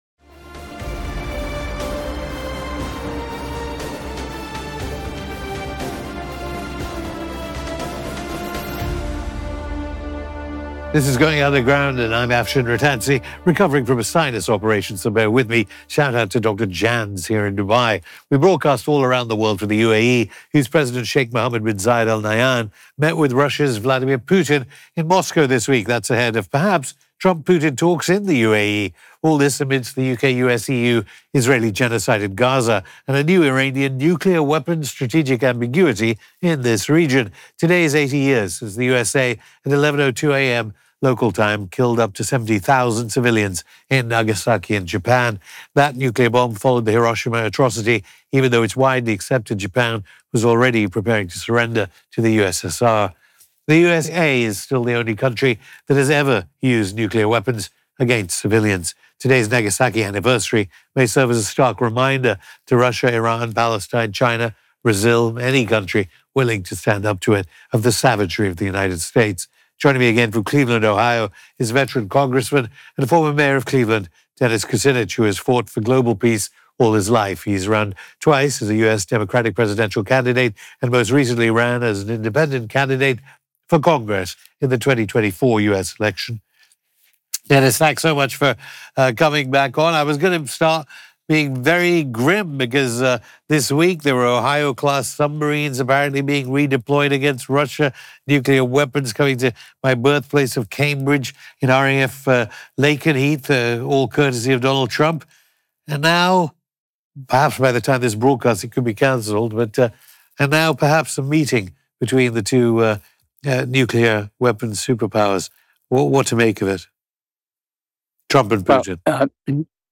Hosted by Afshin Rattansi